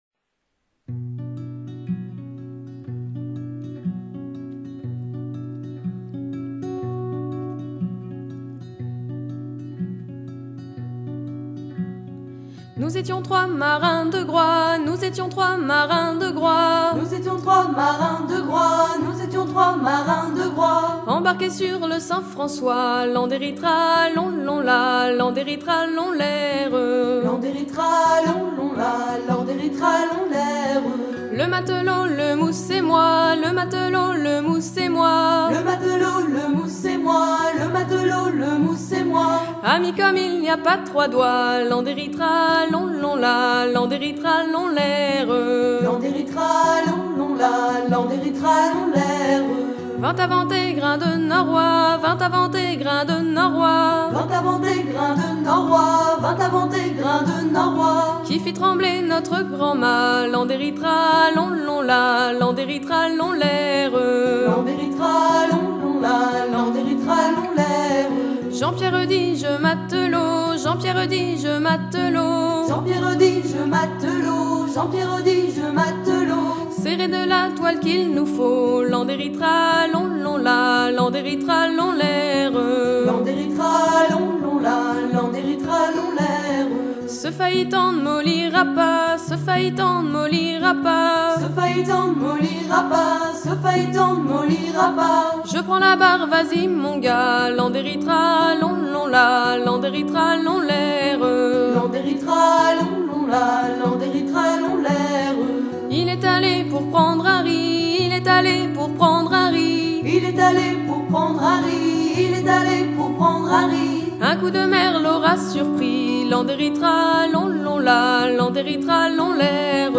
laisse
Pièce musicale éditée